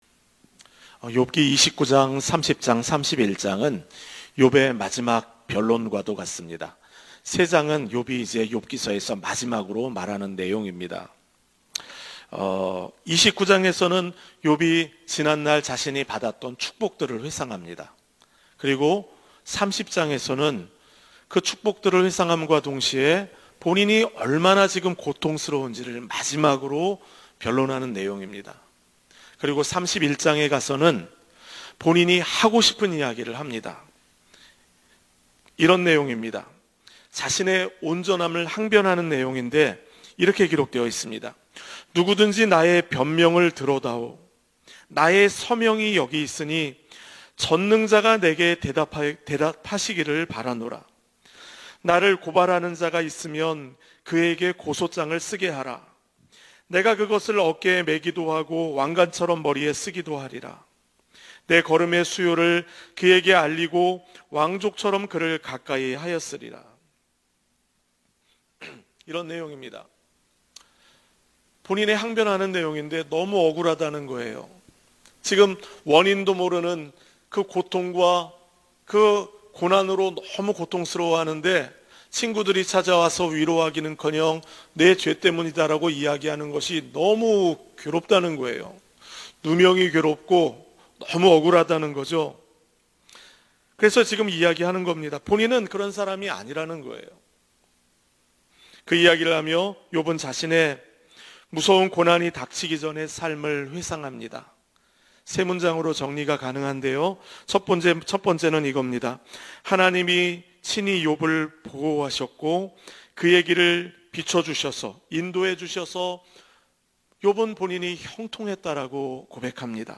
예배: 평일 새벽